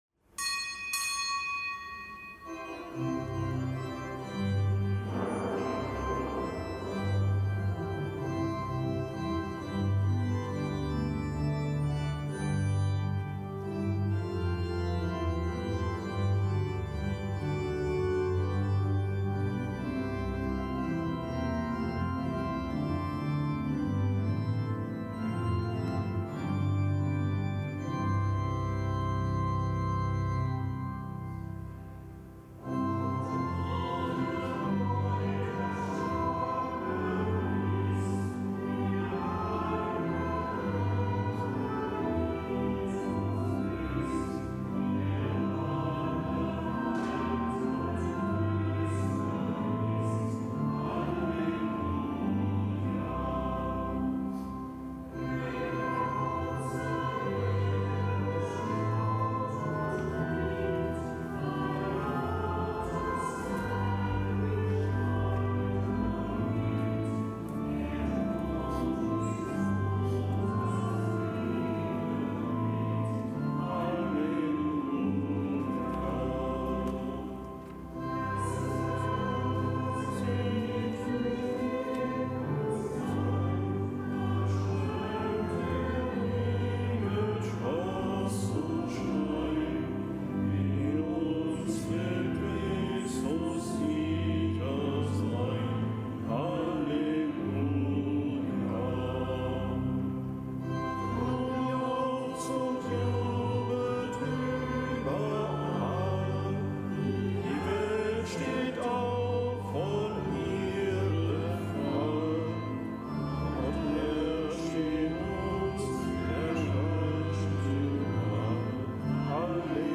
Kapitelsmesse aus dem Kölner Dom am Freitag der Osteroktav